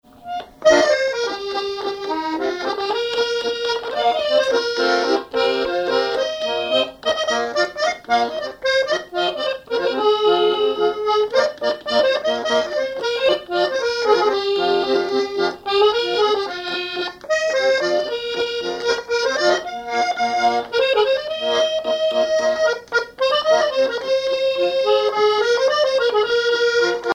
accordéon(s), accordéoniste
Genre strophique
Répertoire à l'accordéon chromatique
Pièce musicale inédite